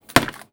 R - Foley 128.wav